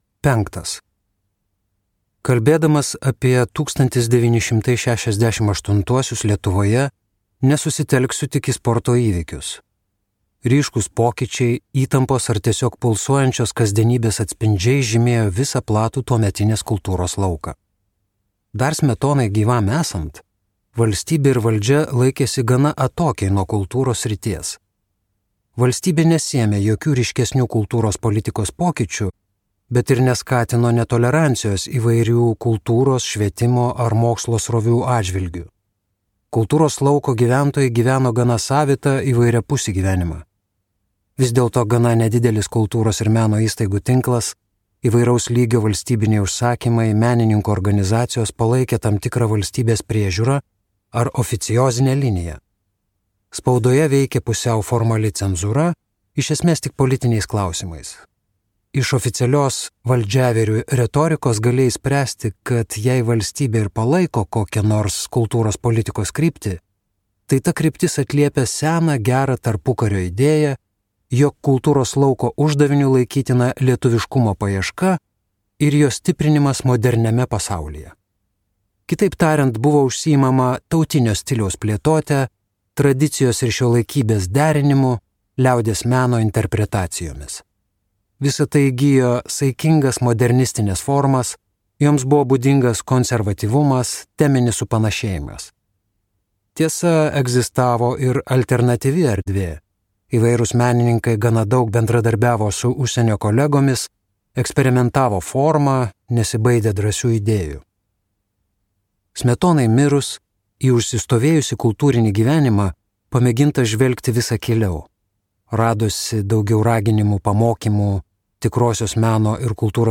FADO. Trumpa neįvykusi Lietuvos istorija | Audioknygos | baltos lankos